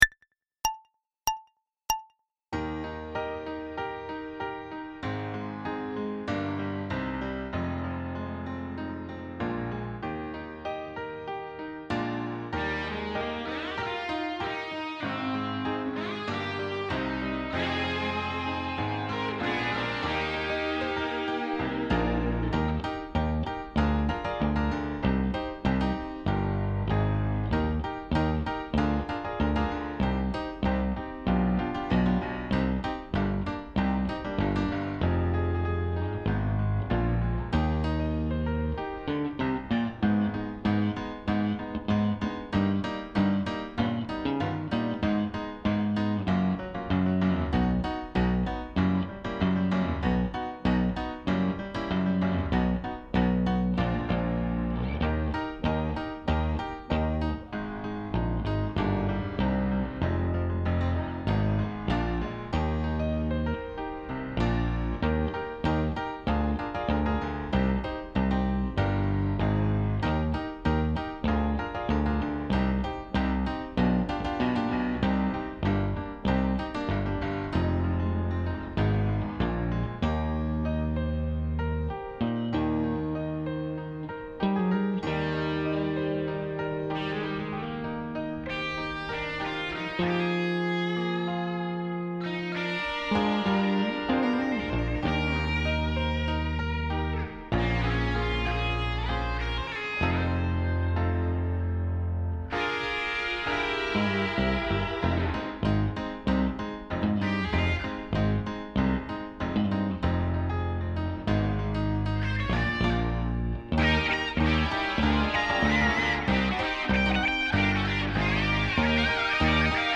• Scarica la base: pianoforte – basso – chitarra –